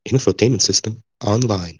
infotainment-system-online.wav